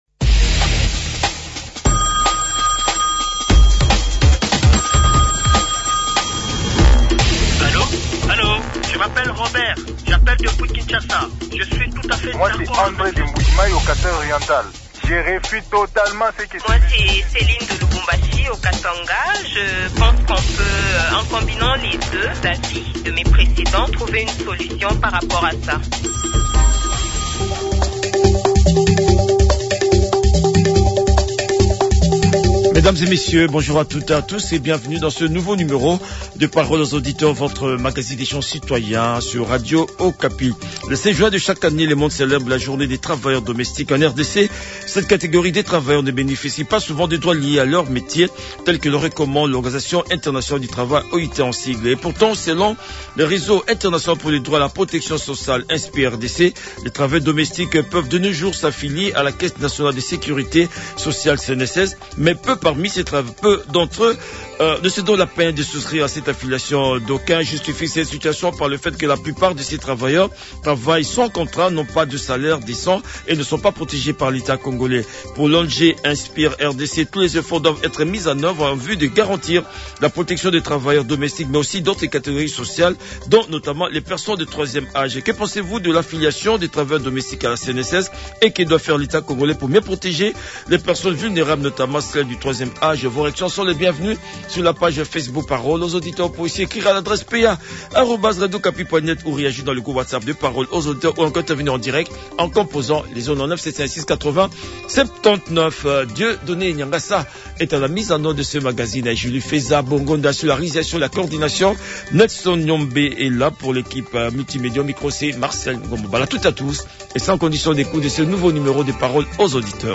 Les auditeurs ont échangé avec les activistes de Réseau International pour le Droit à la protection sociale (INSPIR RDC)